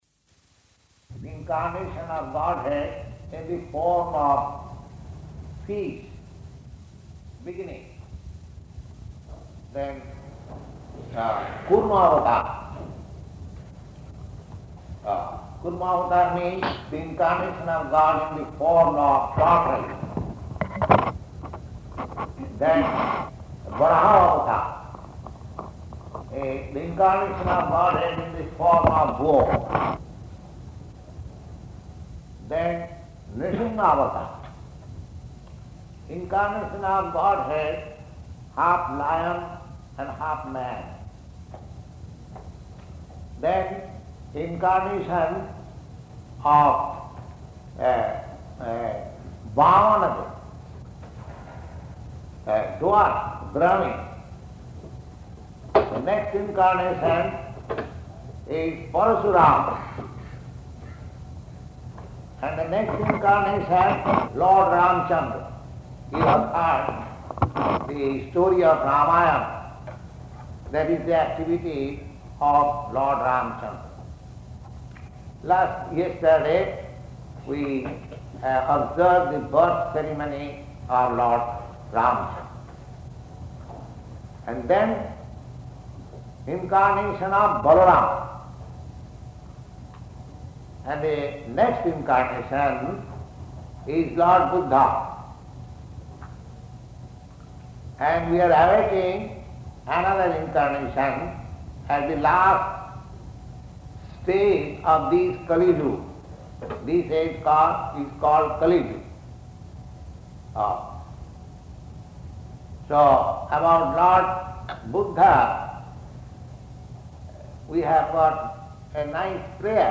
Lord Buddha's Appearance Day Lecture
Type: Lectures and Addresses
Location: Los Angeles